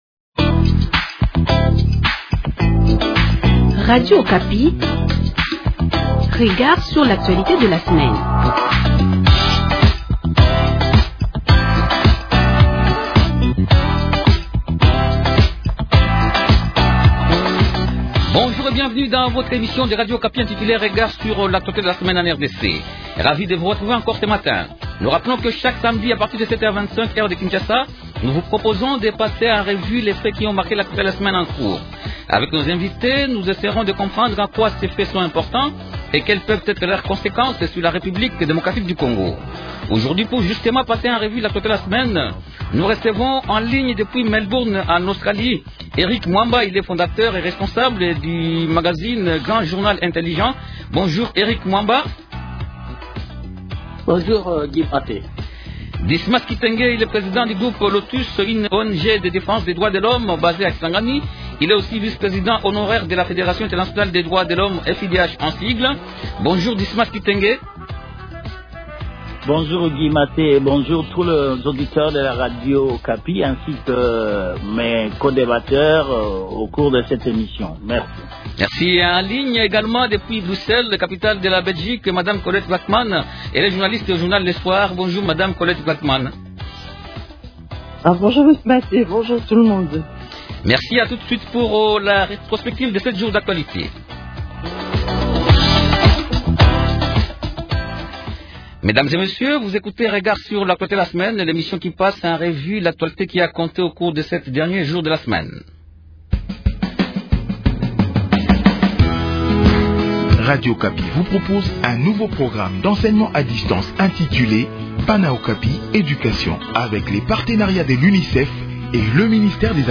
Invités : -En ligne depuis Melbourne en Australie